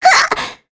Mirianne-Vox_Attack2.wav